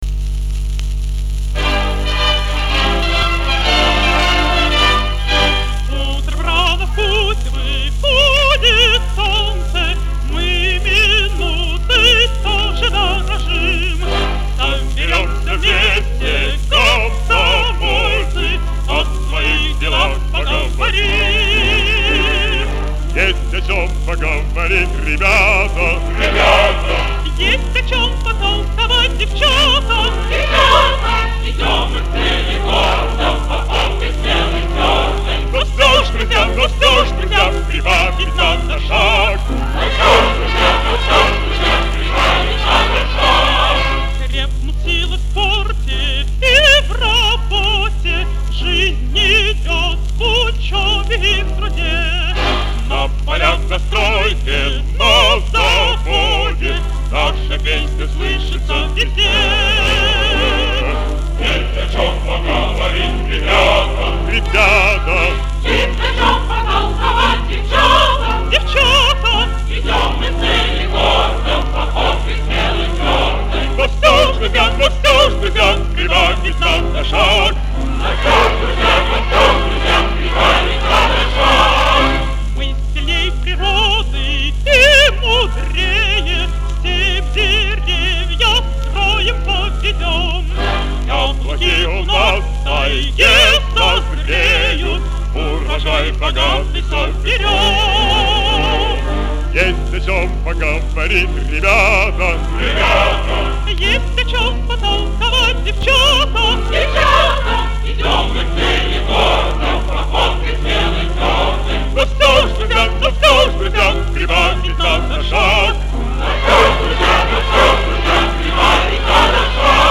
Описание: Бодрая молодежная песня